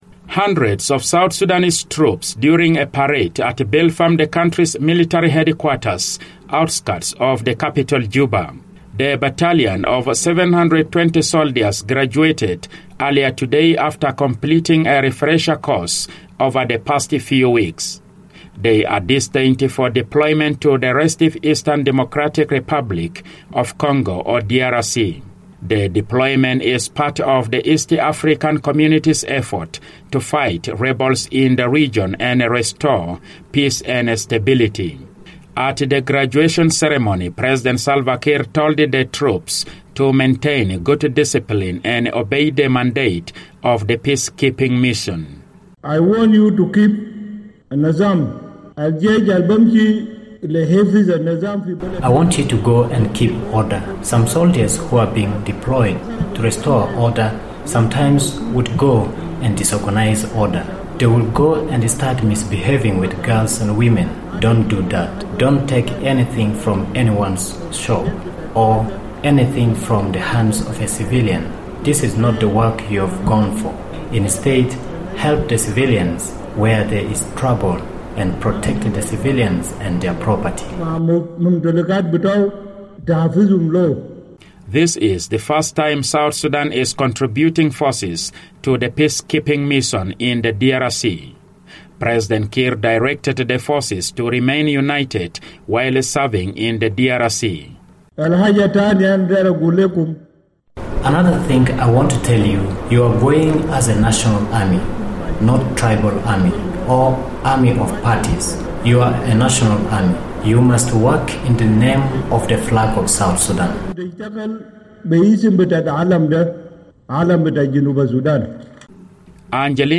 The South Sudan government on Wednesday graduated 720 troops who will be sent to the Democratic Republic of Congo on a peacekeeping mission. Addressing the troops at South Sudan’s military headquarters outside Juba, President Salva Kiir called on them to maintain maximum discipline on their mission.